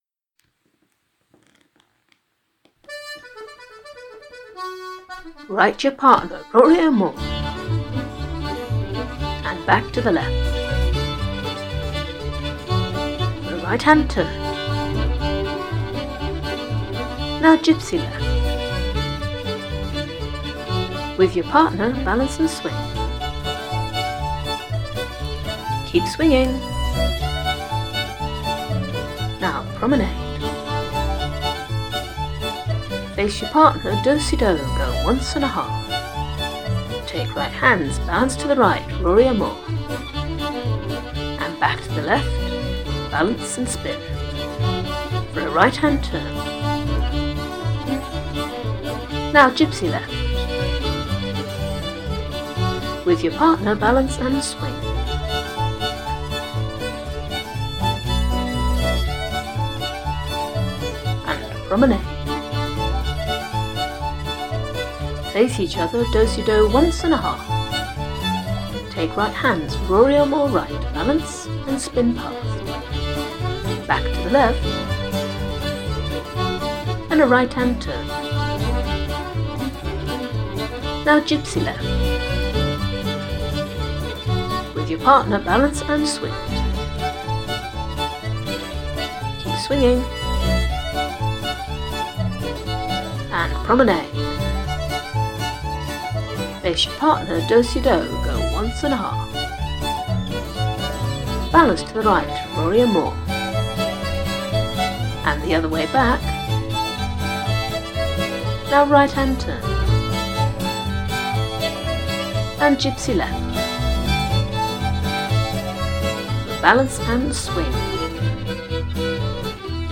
The project this month is a contra!